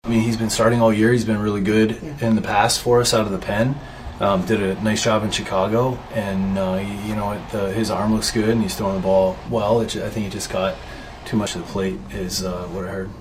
Kelly says Carmen Mlodzinski just had a tough night.